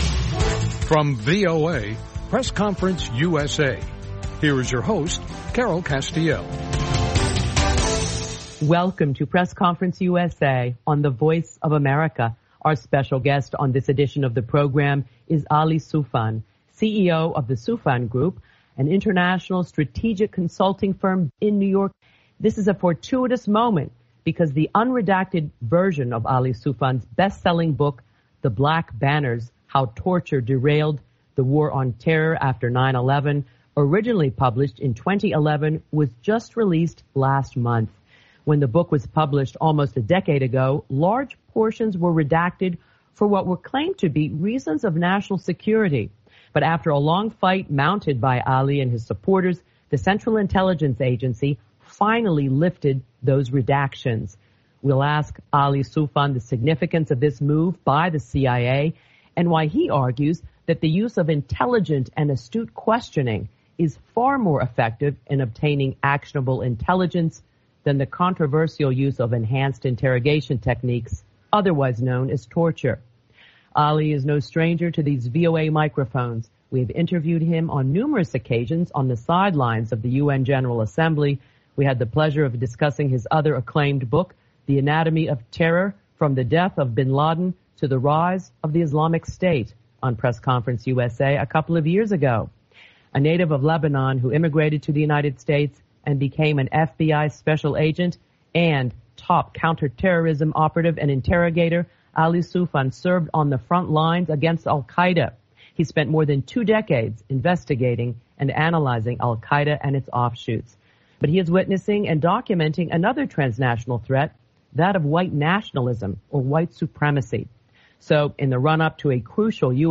A Conversation with Ali Soufan